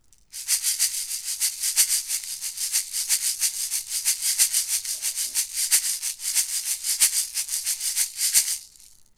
Klang des Audio-Technica AT2020USBi
Shaker
Der Klang ist hat präsente Mitten und viel »Körper«.
Die Membran des Audio-Technica AT2020USBi reagiert schnell auf Transienten und kommt ebenso schnell zur Ruhe.
audio-technica_at2020usbi_test__shaker.mp3